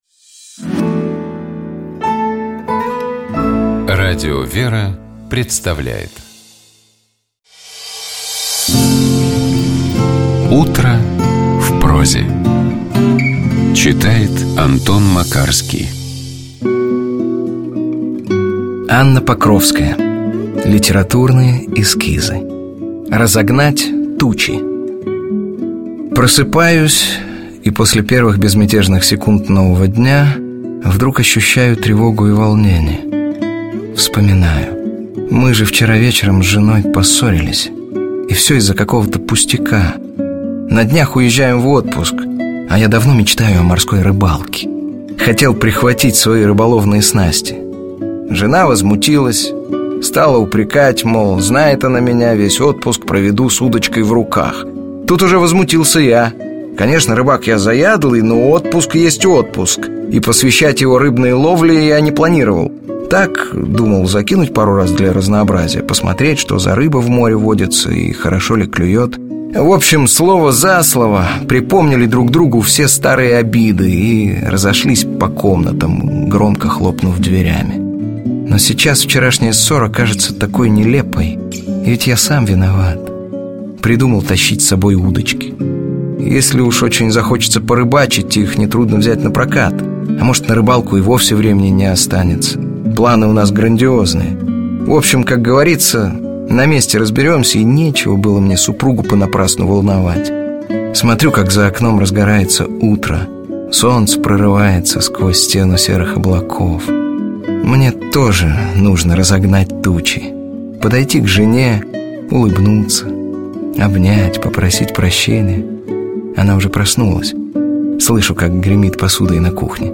Текст Анны Покровской читает Антон Макарский.